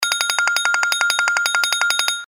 без слов
короткие
звонкие